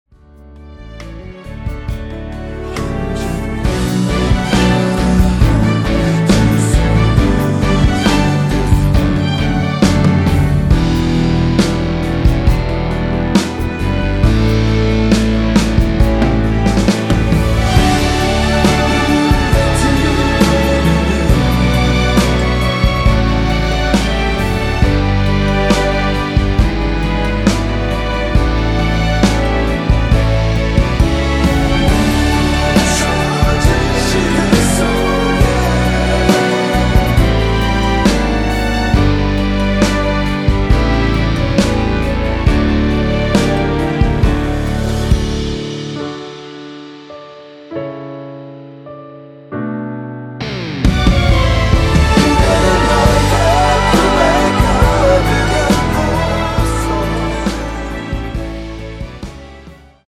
원키에서(-2)내린 코러스 포함된 MR입니다.
앞부분30초, 뒷부분30초씩 편집해서 올려 드리고 있습니다.